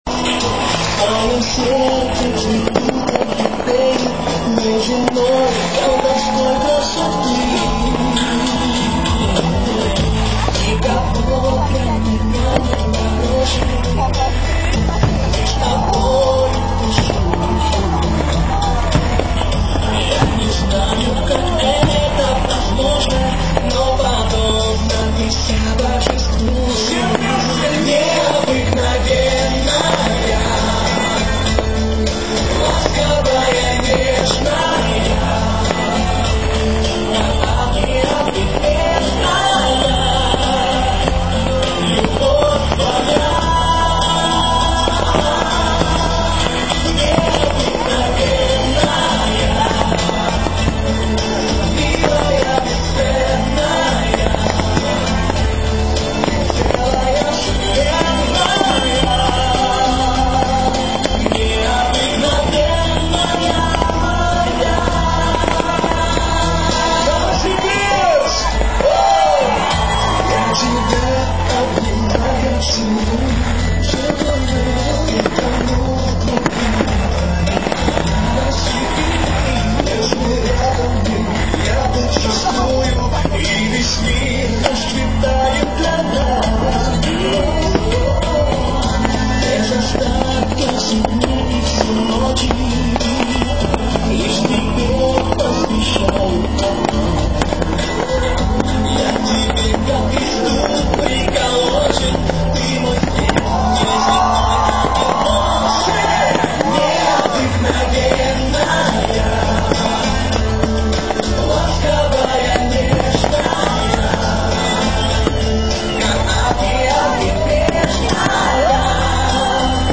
НОВОСИБИРСК, ПЛОЩАДЬ ЛЕНИНА, 4 НОЯБРЯ 2005 ГОДА
под звуки салюта